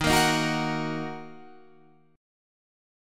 Ebm chord